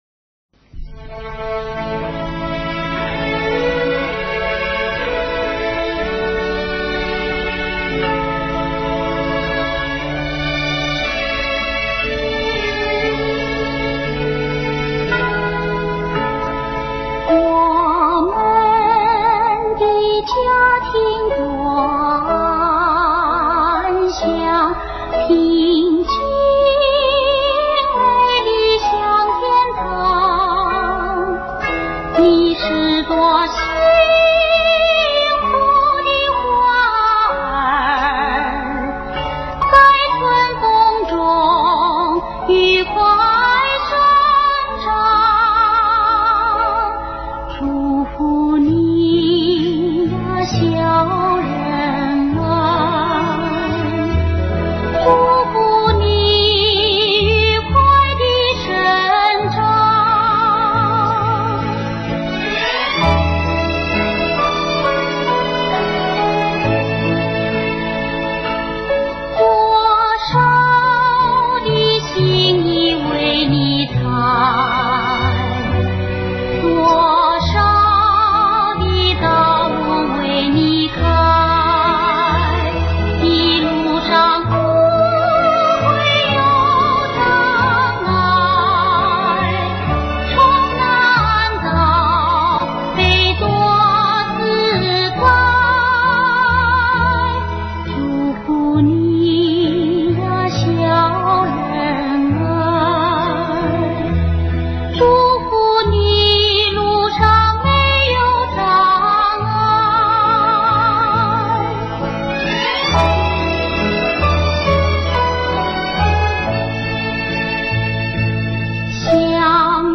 国语时代曲。。。